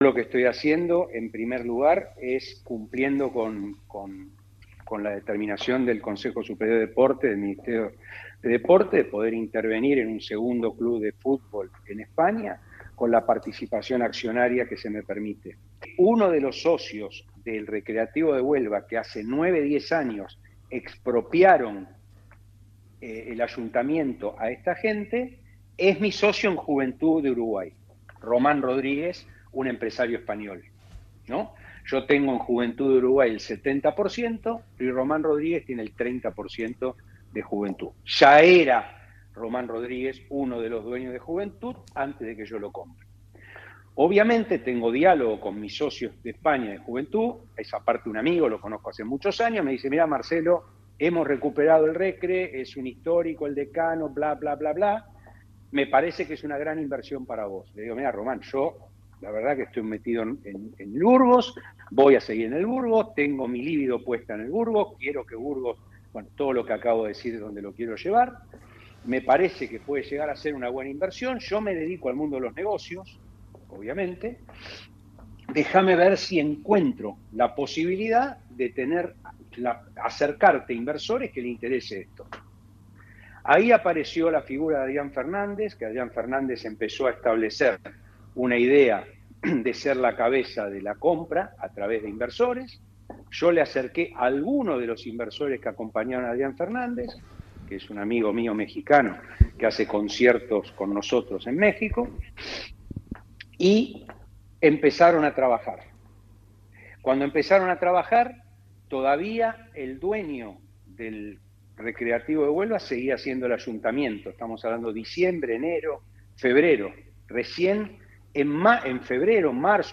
en declaraciones realizadas a Radio Marca Burgos